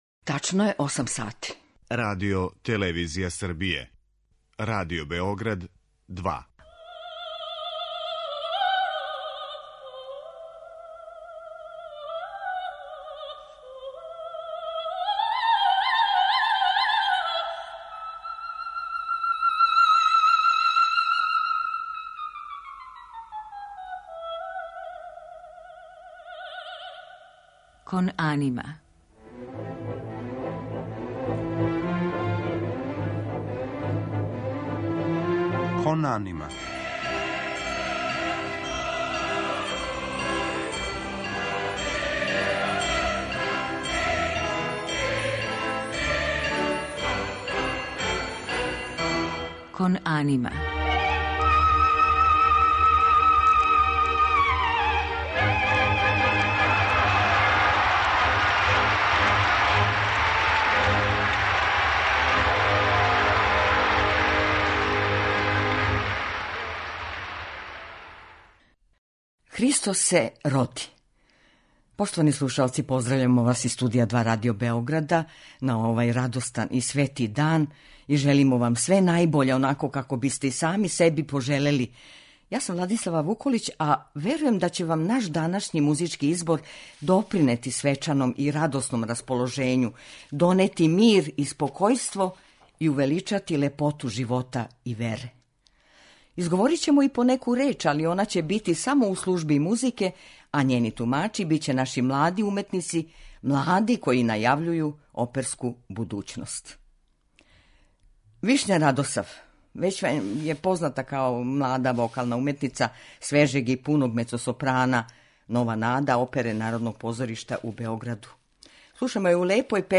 Божићно издање емисије оперске музике